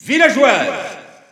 Announcer pronouncing female Villager in French PAL.
Villager_F_French_EU_Announcer_SSBU.wav